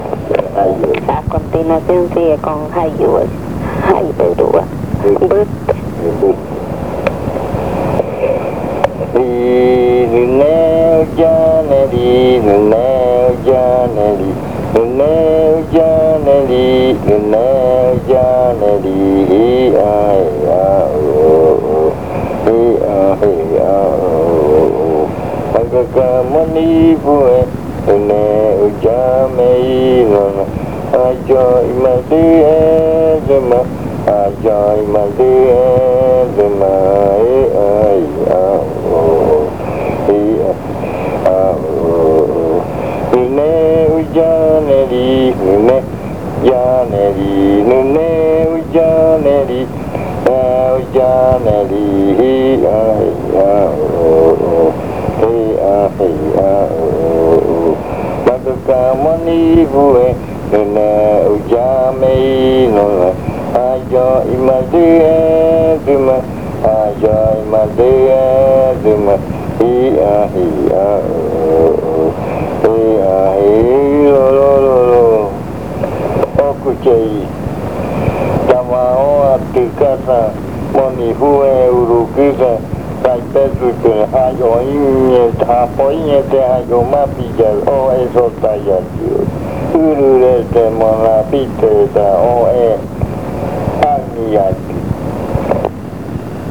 Leticia, Amazonas
En esta canción se baila con unos pasos muy fuertes, brincando y doblando la rodilla.
In this chant you dance with very strong steps, jumping and bending your knee.